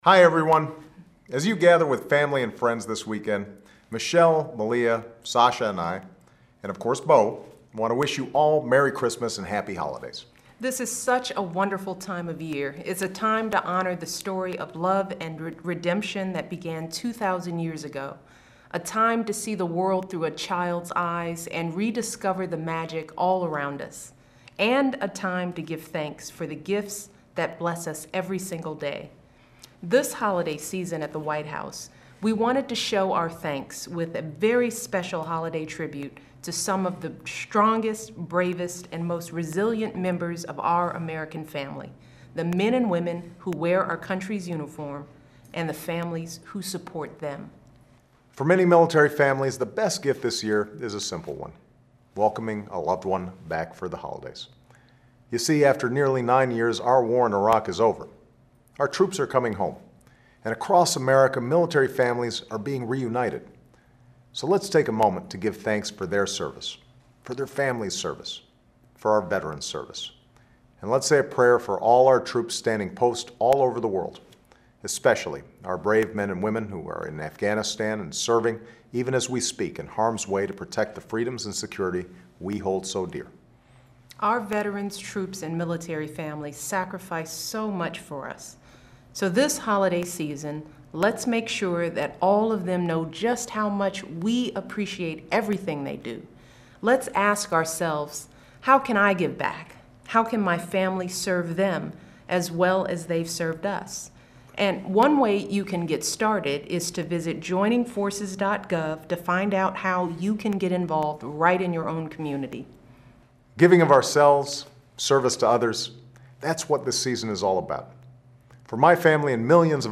Remarks of President Barack Obama and First Lady Michelle Obama
Weekly Address
The White House